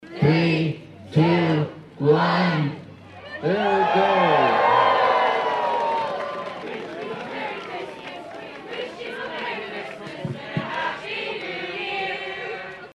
That is the sound of the Kansas State University Marching Band as they marched down Moro Street.